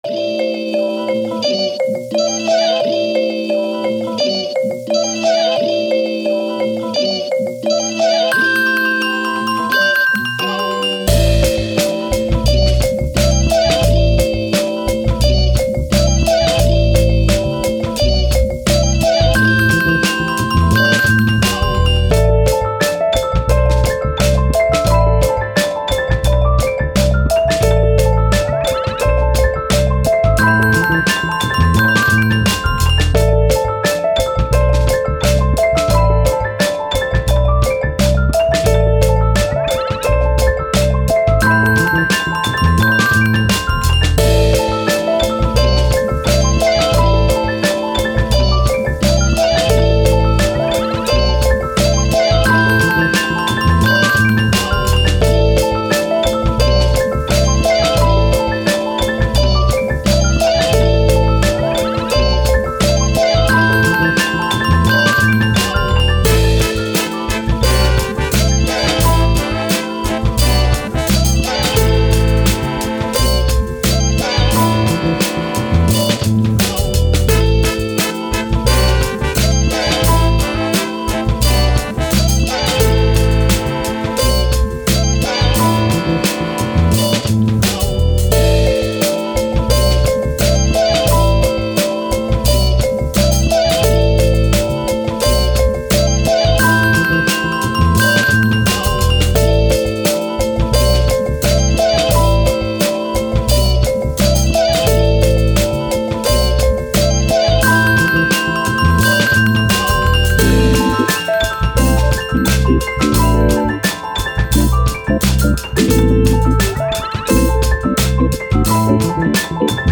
Hip Hop, Action, Cool, Vibe, Positive, Journey